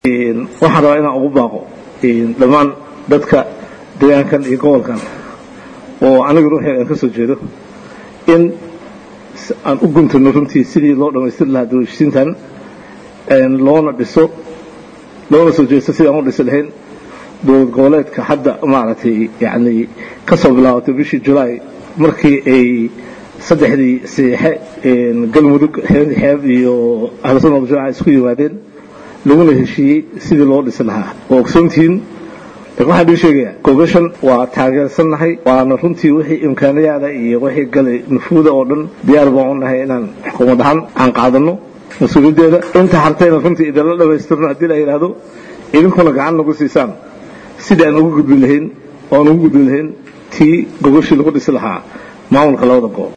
Hadalkaan ayaa waxa uu ka sheegay Xarunta Shirka Dhuusamareeb uu uga soconayo Beelaha Mudug iyo Galgaduud ee Soomaaliya.